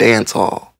Dancehall.wav